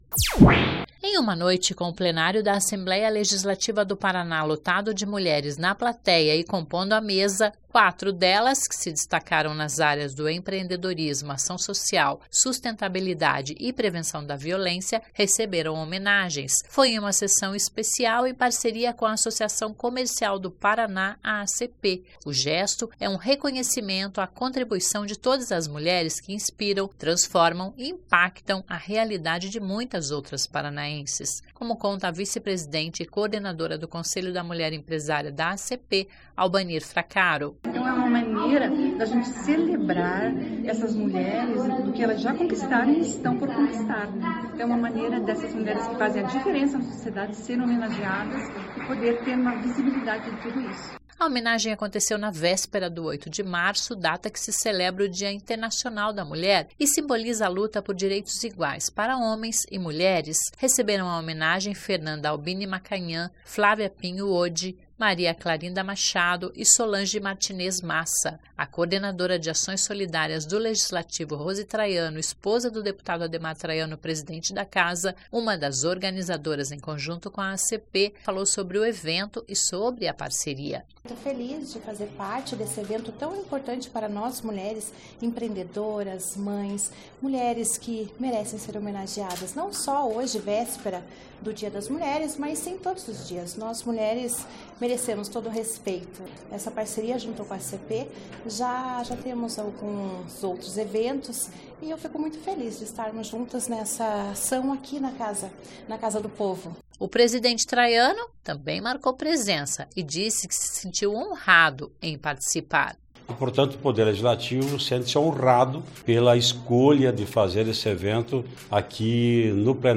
Evento aconteceu no Plenário da Assembleia na noite desta terça-feira (7).
(Sonora)